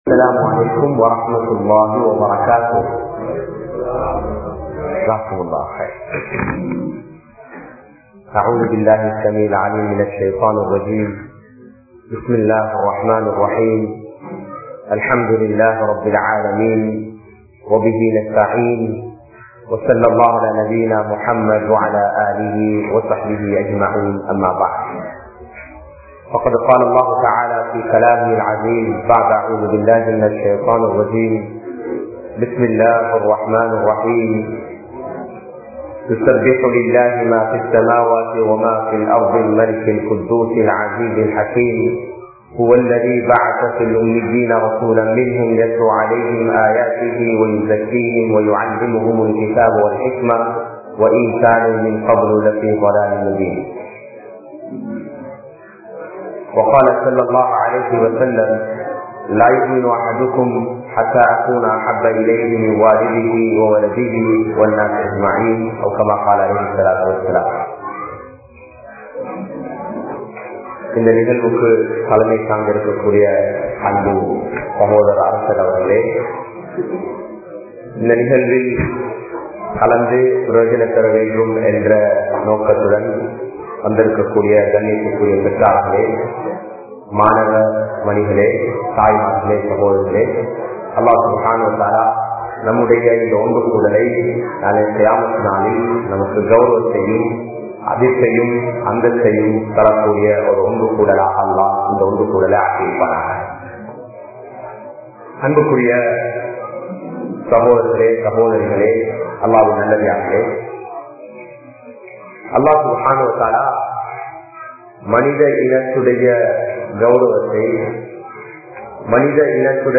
Dhauwath Entraal Enna? (தஃவத் என்றால் என்ன?) | Audio Bayans | All Ceylon Muslim Youth Community | Addalaichenai